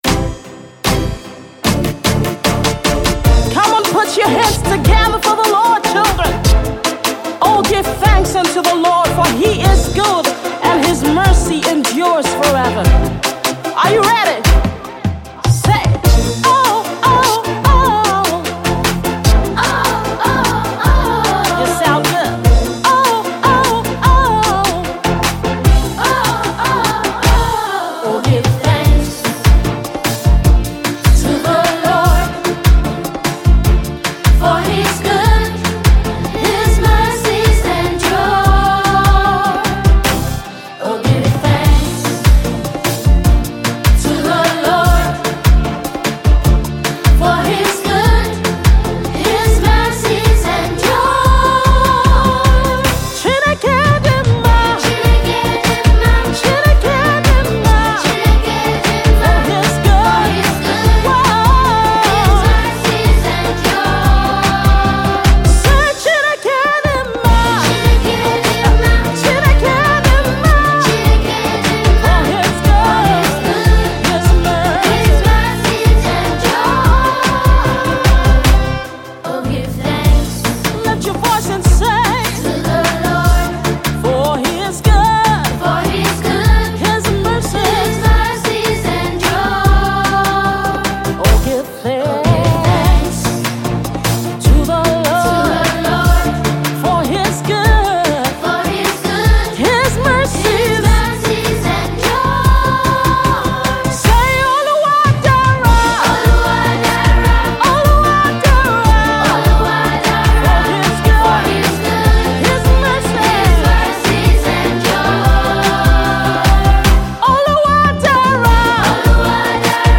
This project isn’t just songs for kids But sung by kids too.